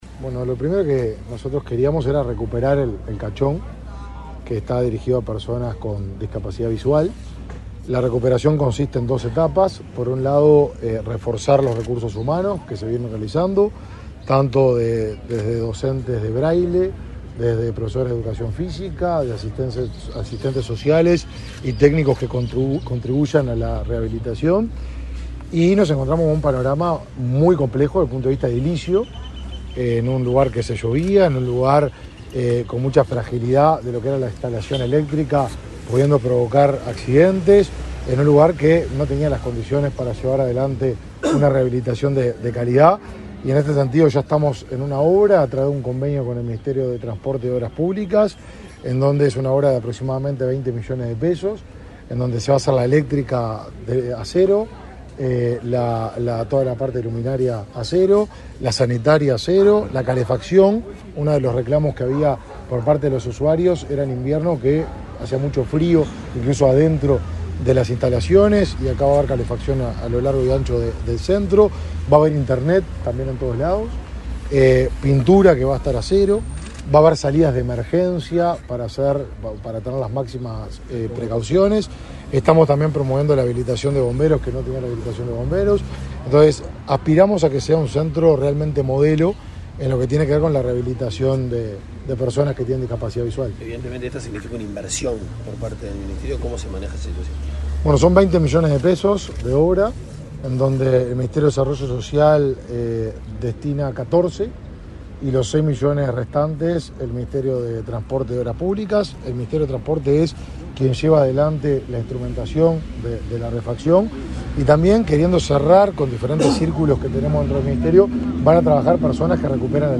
Declaraciones a la prensa del ministro de Desarrollo Social, Martín Lema
El ministro de Desarrollo Social, Martín Lema, y autoridades del Ministerio de Transporte y Obras Públicas recorrieron las obras que se efectúan en el Centro de Rehabilitación Tiburcio Cachón para personas con discapacidad visual, resultado del convenio firmado entre ambas secretarías de Estado. Tras la visita, Lema realizó declaraciones a la prensa.